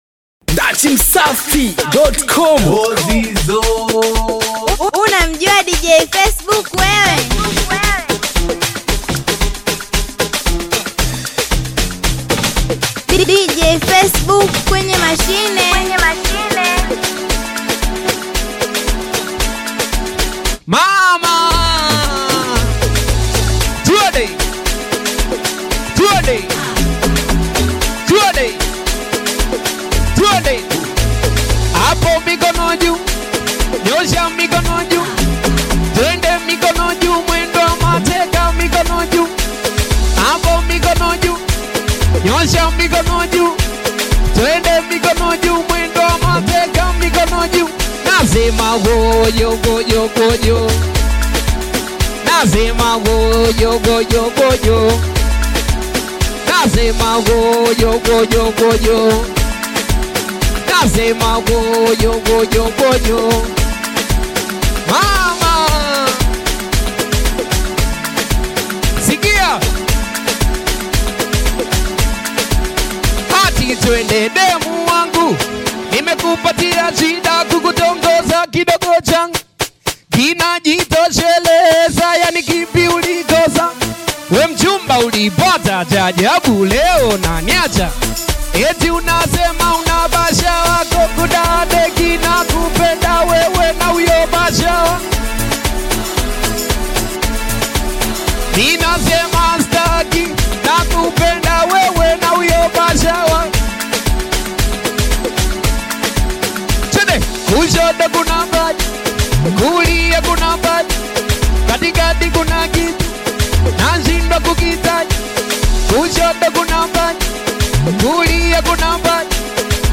Dj Mix / Beat